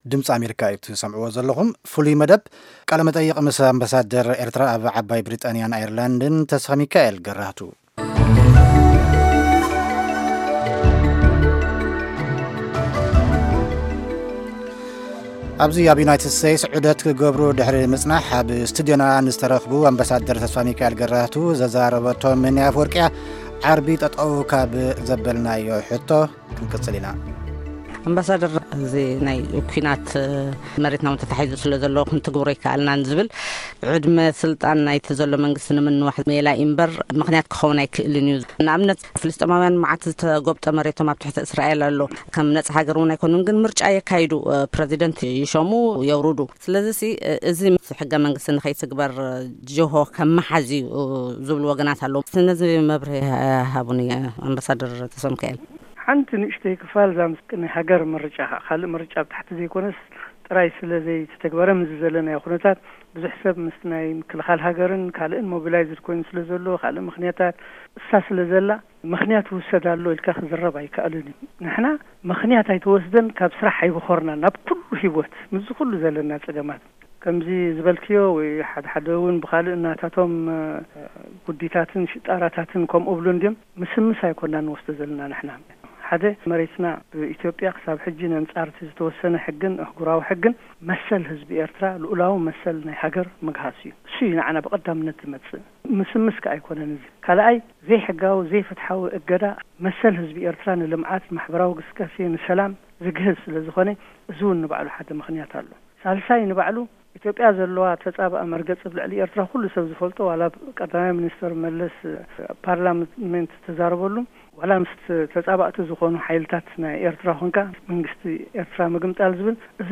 ምስ ኣምባሳደር ተ/ሚካኤል ገራህቱ ዝተካየደ ቃለ-ምልልስ (2ይ ክፋል)